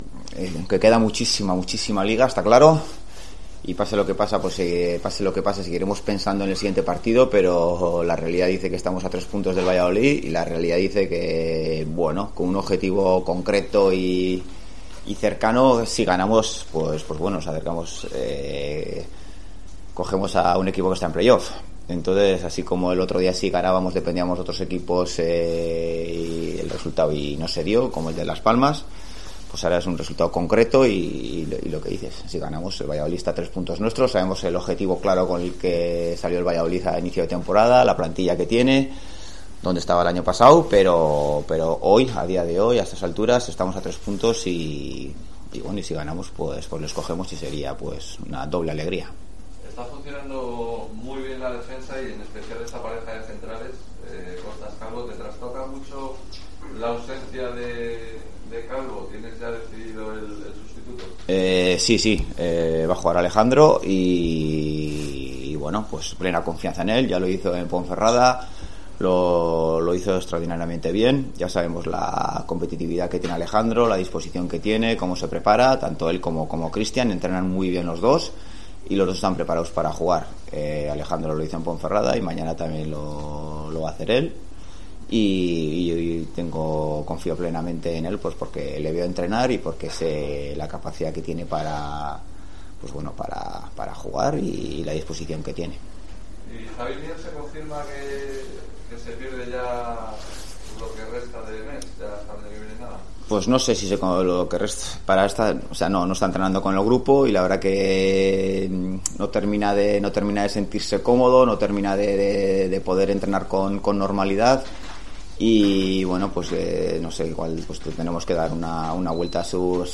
Rueda de prensa Ziganda (Valladolid-Oviedo)